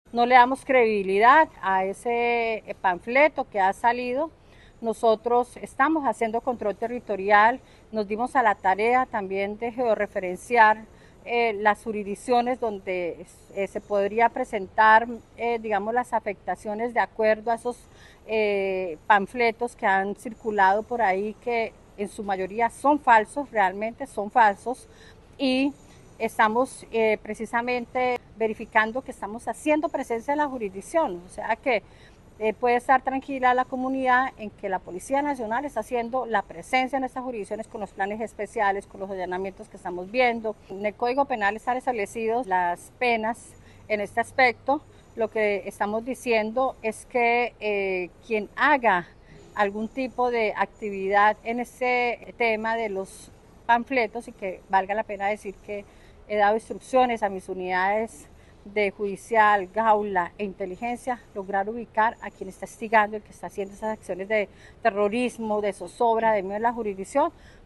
Coronel Adriana Paz Fernández, Comandante Departamento de Policía Magdalena Medio